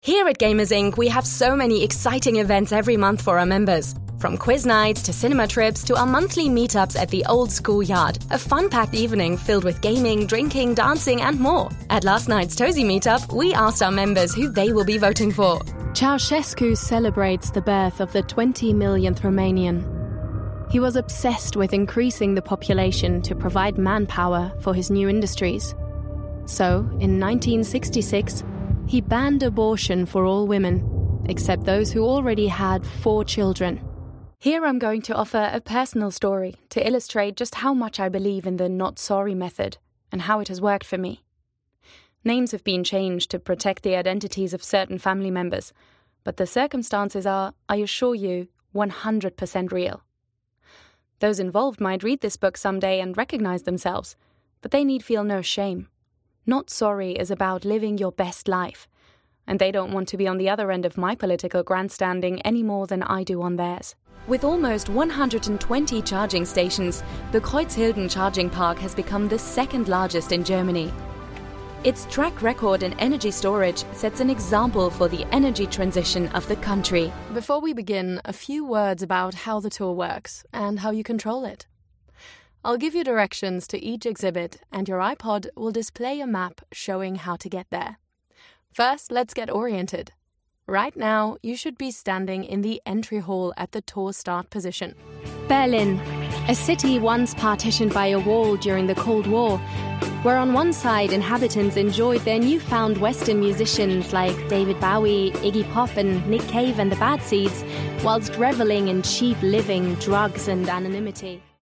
Narración
Mi voz es identificable, contemporánea y juvenil con un sonido cálido y texturizado.
Desde mi estudio hogareño con calidad de transmisión en Londres, he trabajado con cientos de clientes de todo el mundo, entregando un trabajo de primera calidad en alemán, inglés y francés.